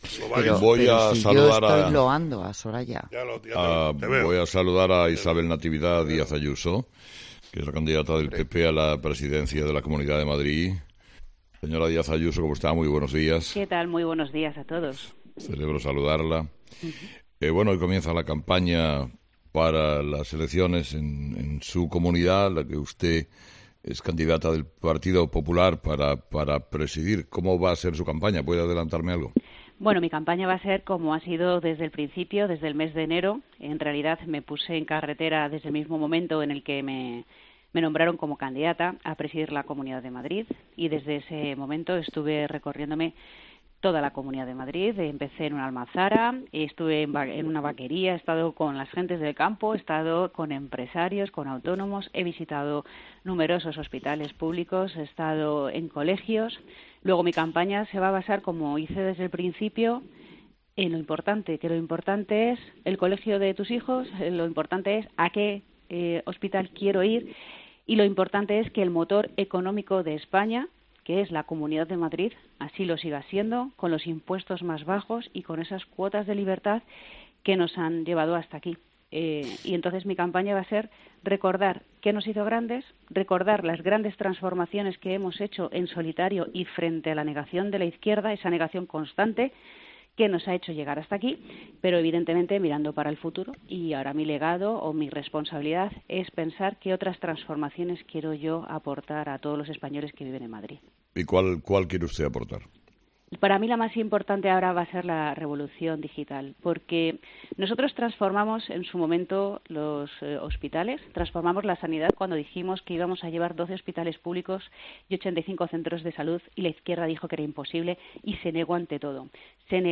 La candidata del PP a la Comunidad de Madrid asegura en 'Herrera en COPE' que  después de los resultados del PP en las generales “hay que hacer reflexión”